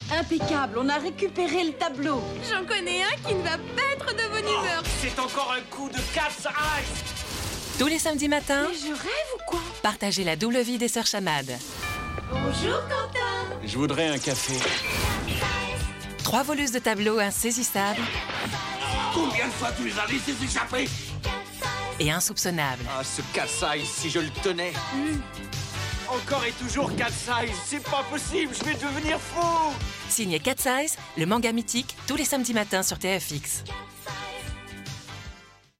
Bande Démo fiction
30 - 50 ans - Mezzo-soprano